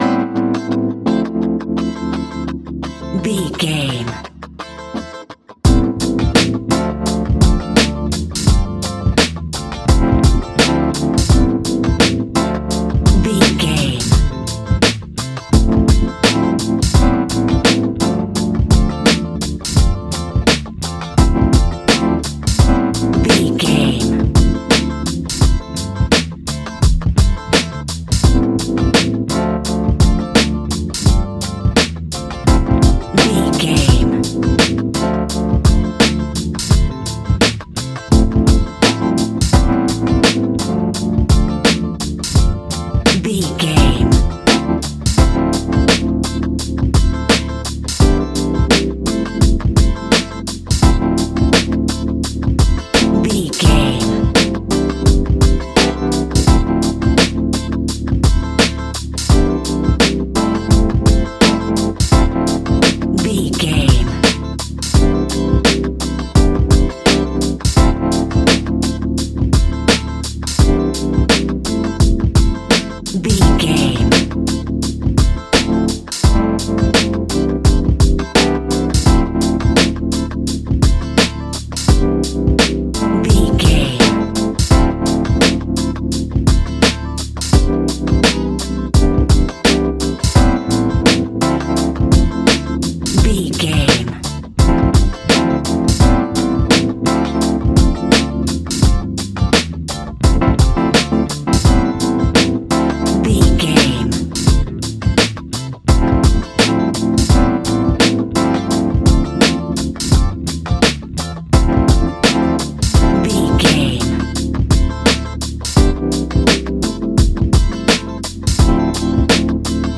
Ionian/Major
D
laid back
Lounge
sparse
new age
chilled electronica
ambient
atmospheric